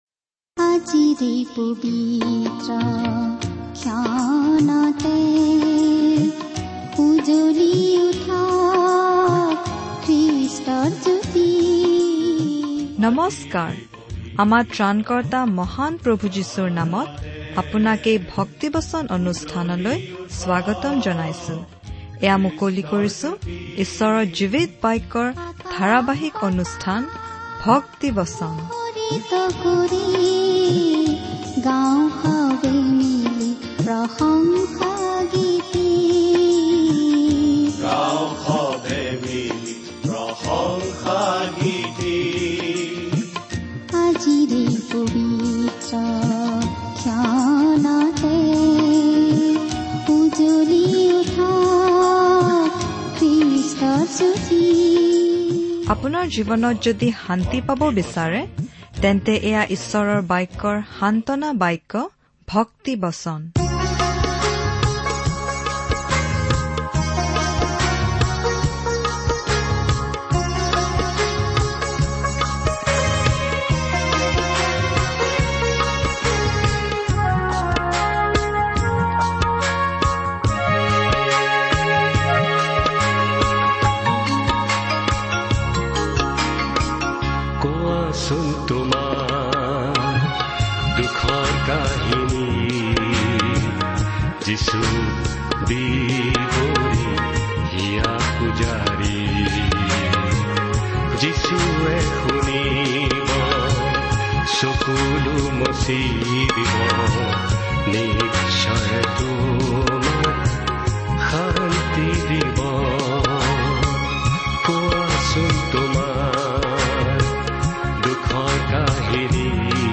প্ৰতিদিনে ৩০ মিনিটৰ এই অনাতাঁৰ অনুষ্ঠানসমুহ এনেদৰে সুশৃংখলভাৱে সজোৱা হৈছিল যে এজন শ্ৰোতাই সহজতেই বাইবেলখন শিকিব পাৰে ৷